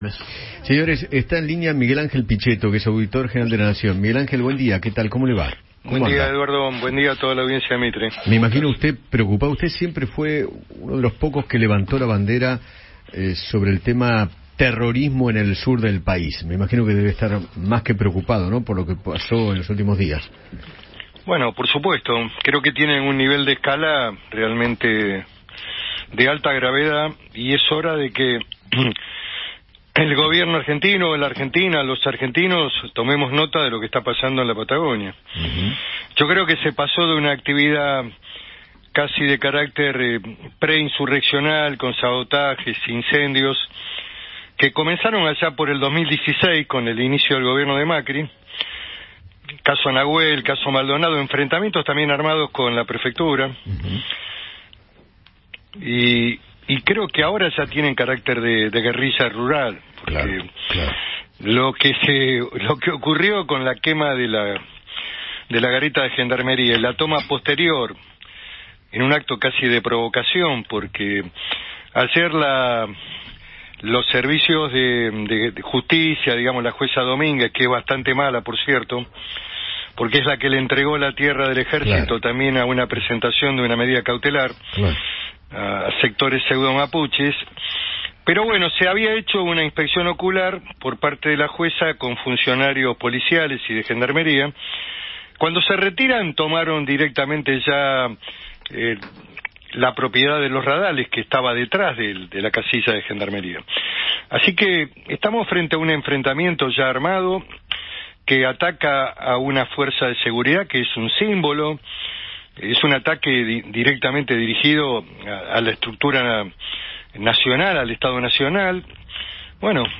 Miguel Ángel Pichetto, Auditor General de la Nación, charló con Eduardo Feinmann sobre los ataques mapuches en la Patagonia y la disputa por el territorio.